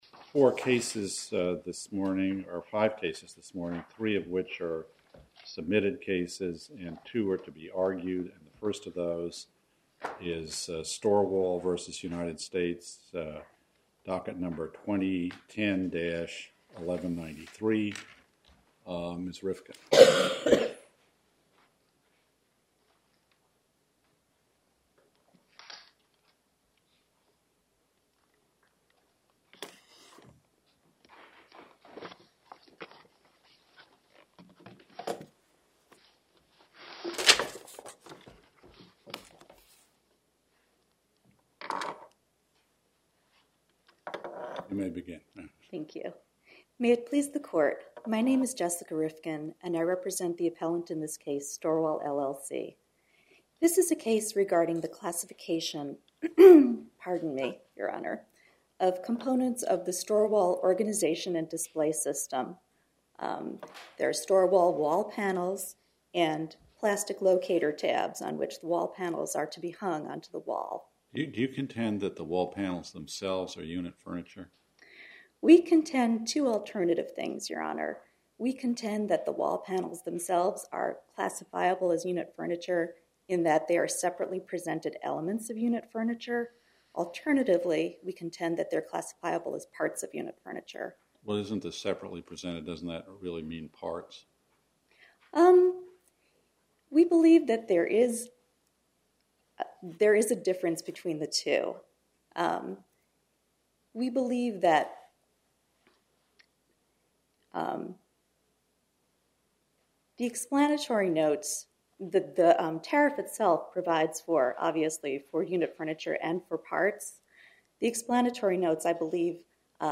Oral argument audio posted: STOREWALL V. US (mp3) Appeal Number: 2010-1193 To listen to more oral argument recordings, follow this link: Listen To Oral Arguments.